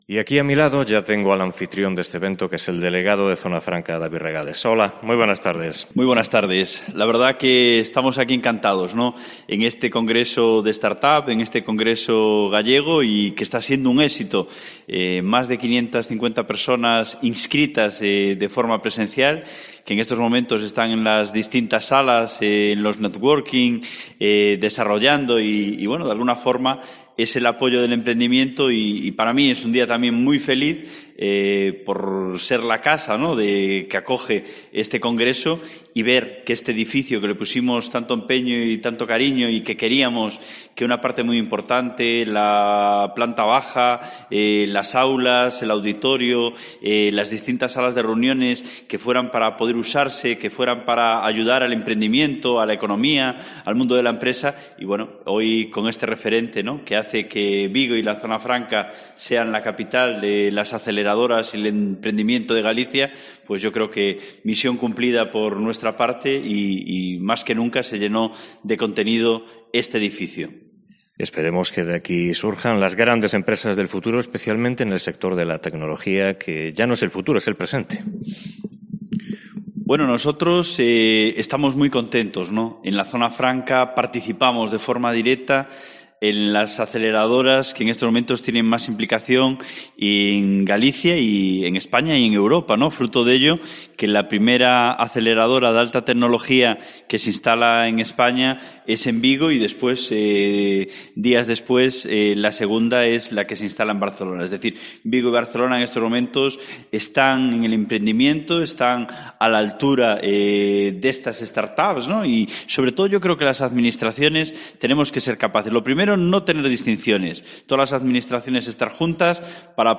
Entrevistamos al delegado de Zona Franca, David Regades